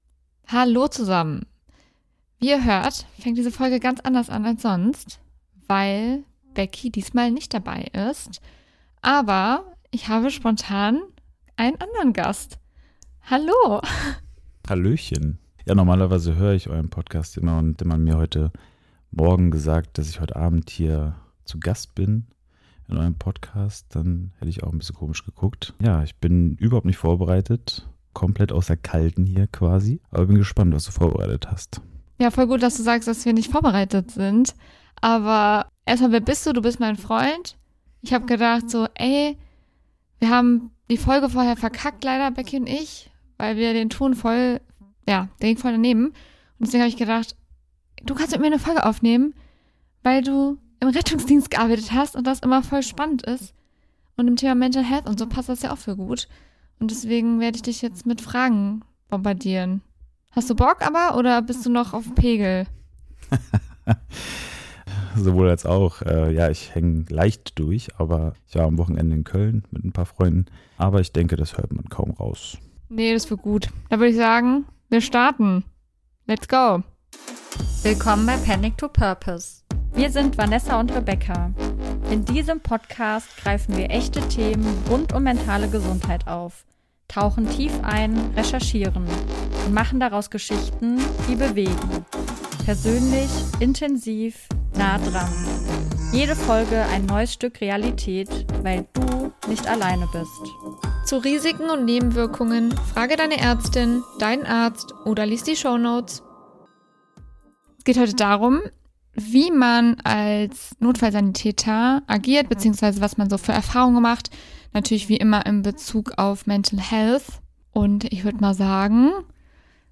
Wir sprechen mit einem Notfallsani über die psychischen Kosten von Adrenalin, die Momente, die man nie vergisst, und den mentalen Crash, wenn plötzlich Ruhe einkehrt.